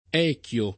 [ $ k L o ]